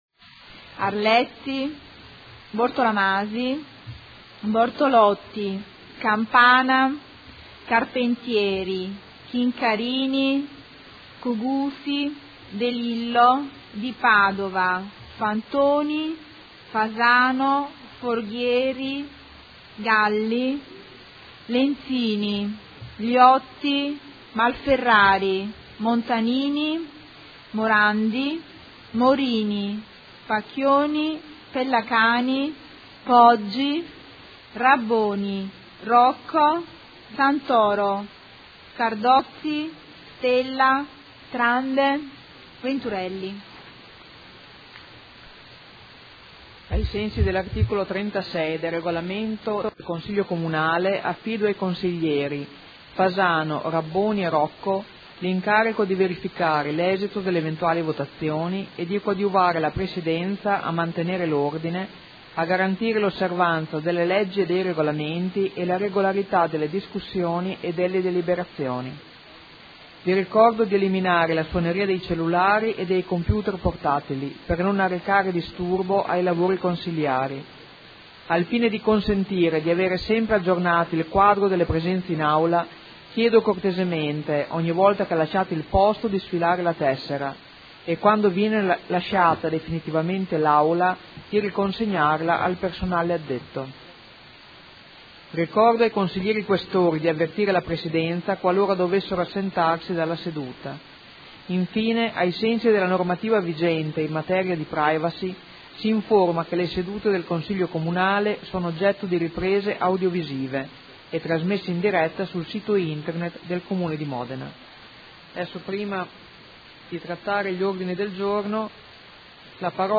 Segretario Generale — Sito Audio Consiglio Comunale
Seduta del 5/11/2015. Appello ed apertura del Consiglio Comunale